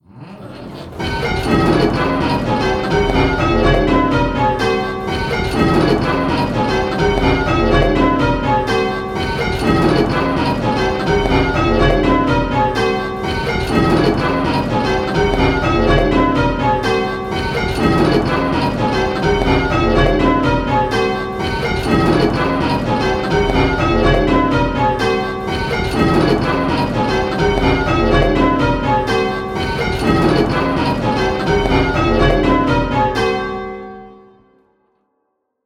Striking 8 Bell Rounds - Pebworth Bells
Striking 8 Bell Rounds - Round 1